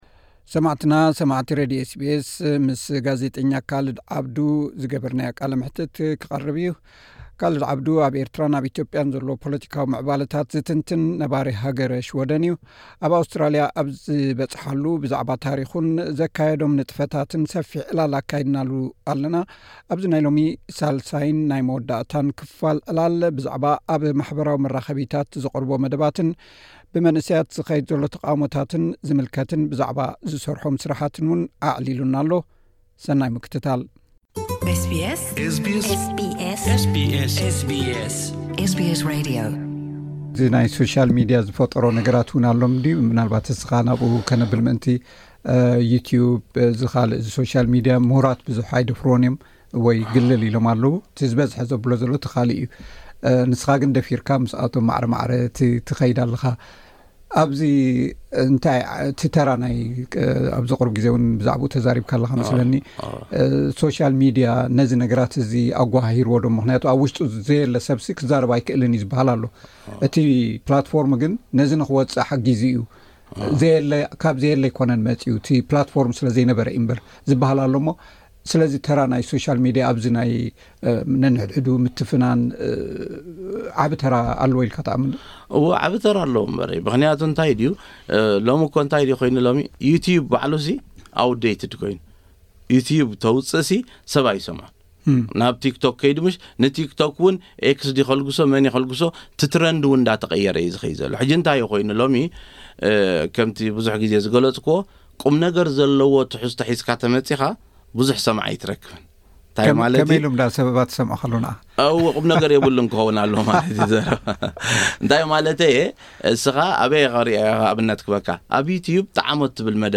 ኣብ ኣውስትራሊያ ኣብ ዝበጽሓሉ ብዛዕባ ታሪኹን ዘካይዶም ንጥፈታትን ሰፊሕ ዕላል ኣካይድናሉ ኣለና። ኣብዚ ናይ ሎሚ ሳልሳይን ናይ መወዳእታን ክፋል ዕላል ብዛዕባ ኣብ ማሕበራዊ መራኸቢታት ዘቕርቦ መደባትን ብመንእሰያት ዝኸይድ ዘሎ ተቓዉሞታት ዝምልከቱን ብዛዕባ ስርሑን ኣዕሊሉ ኣሎ።